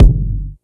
Bassy Kick Sound F# Key 294.wav
Royality free kick sample tuned to the F# note. Loudest frequency: 154Hz
bassy-kick-sound-f-sharp-key-294-4JR.mp3